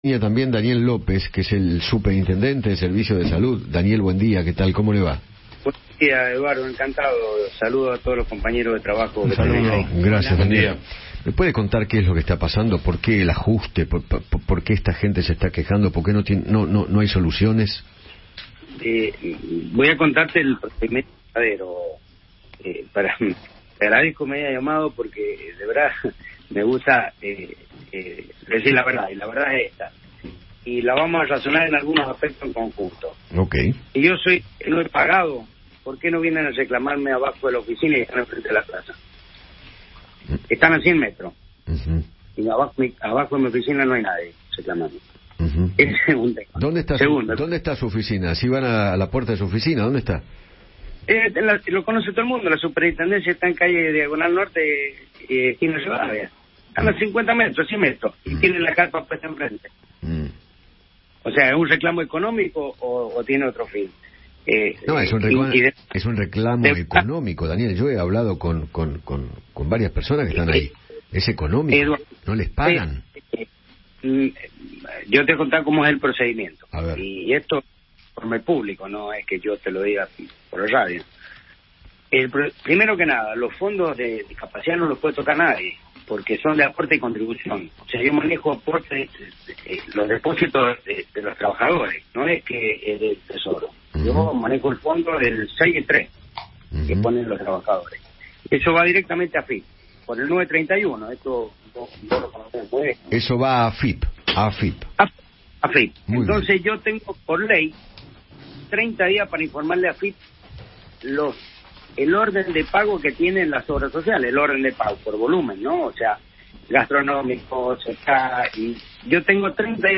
Daniel López, superintendente de Servicios de Salud, conversó con Eduardo Feinmann sobre el reclamo de los prestadores de servicios para personas con discapacidad y sostuvo que “hay que reclamar a las obras sociales. No es problema de la Superintendencia que no se hayan transferido los fondos”.